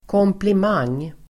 Ladda ner uttalet
Uttal: [kåmplim'ang:]
komplimang.mp3